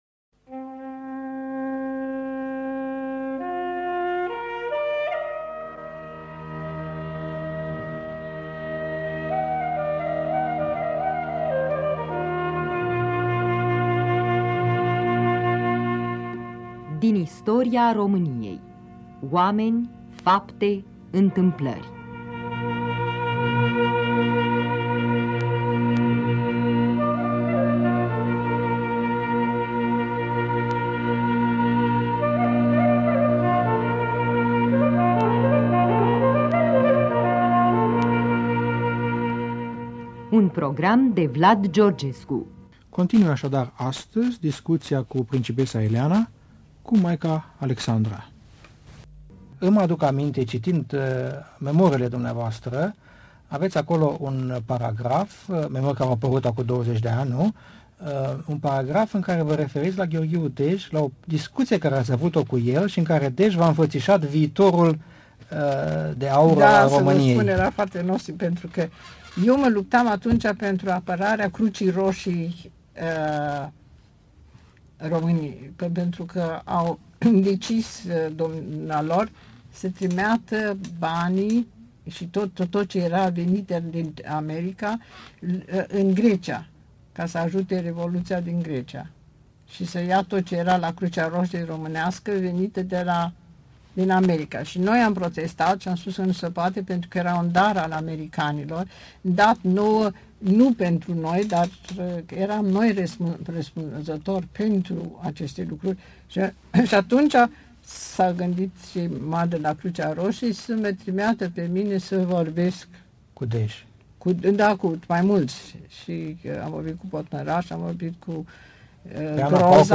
Din istoria României: Vlad Georgescu în dialog cu Principesa Ileana (Maica Alexandra)